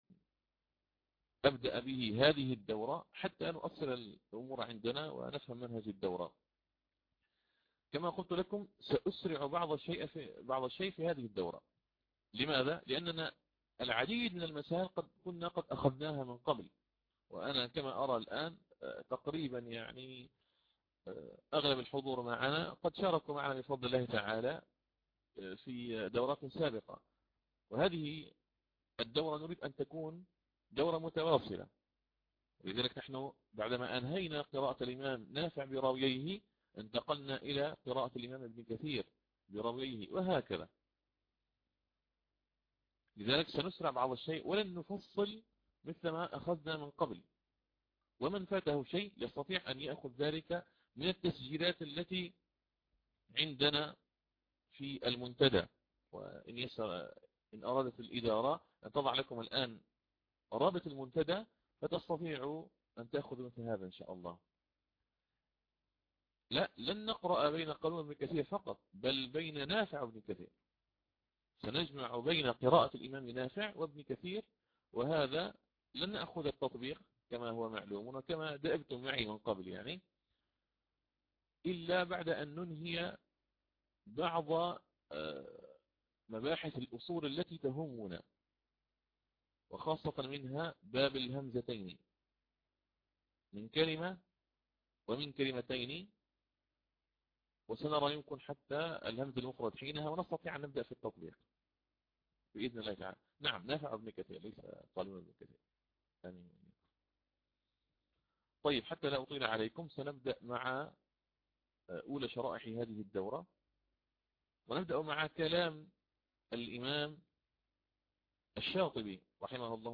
تسجيل الدرس الاول من اصول ابن كثير.mp3